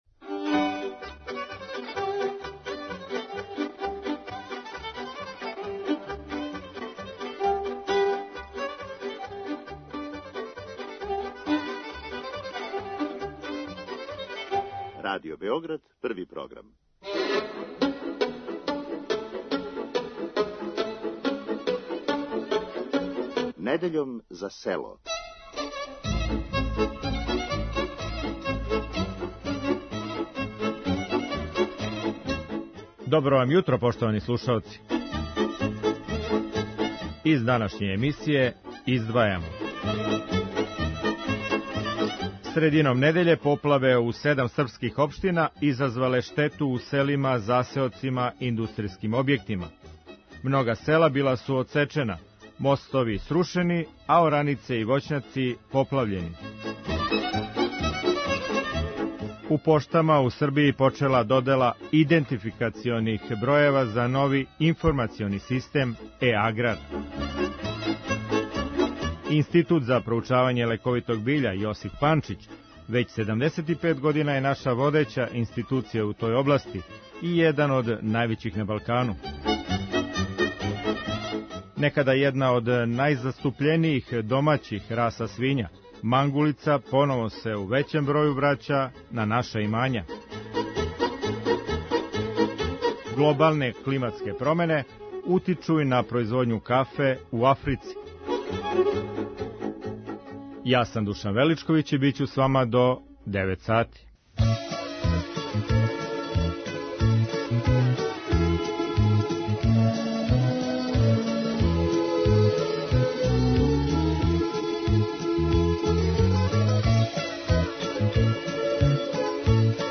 За емисију Недељом за село говоре и мачвански сточари.
Како на њу утичу климатске промене и неуобичајено високе температуре протеклих месеци говоре банатски ратари и агрономи.